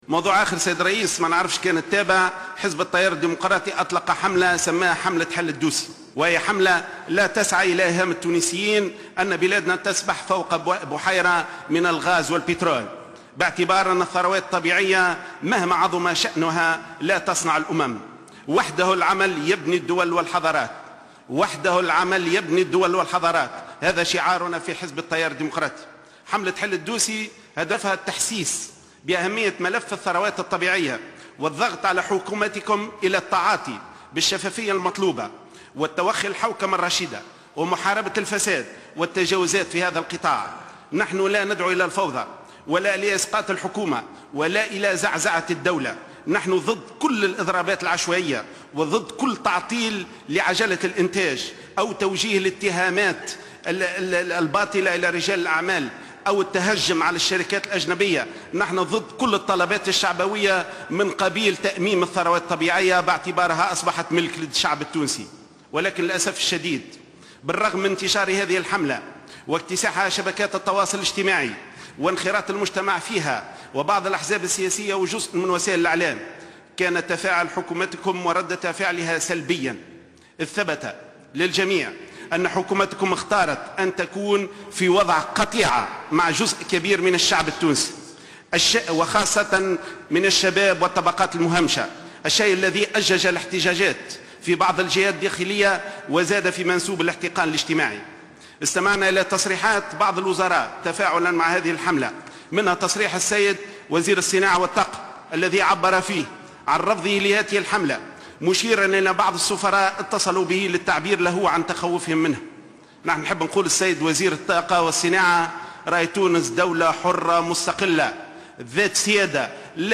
أعلن النائب غازي الشواشي عن التيار الديمقراطي اليوم الجمعة 5 جوان 2015 خلال جلسة عقدت بمجلس نواب الشعب حول المائة يوم الأولى من عمل الحكومة أن حملة "حل الدوسي" ستستمر بقوة وستطال كل المؤسسات التونسية التي تحوم حولها شبهة فساد على حد قوله.